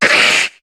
Cri de Carvanha dans Pokémon HOME.